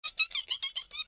AV_rabbit_long.ogg